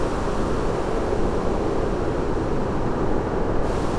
Index of /musicradar/sparse-soundscape-samples/Sample n Hold Verb Loops